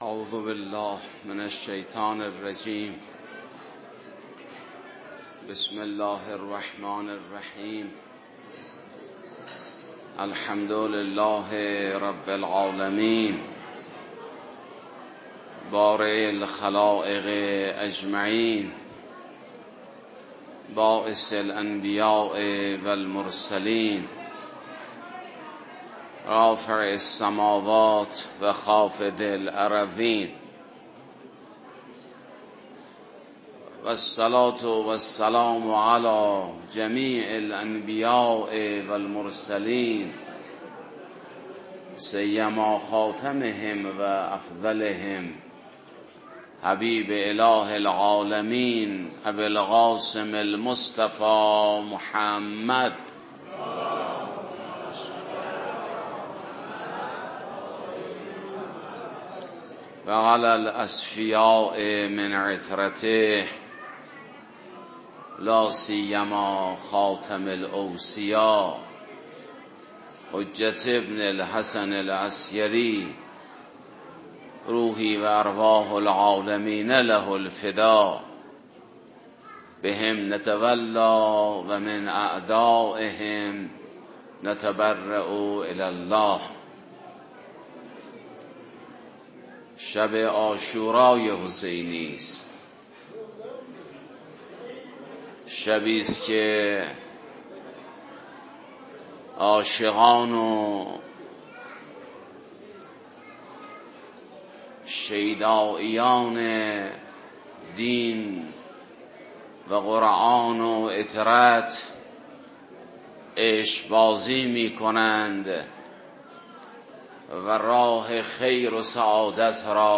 در جمع عزاداران اباعبدالله الحسین (ع)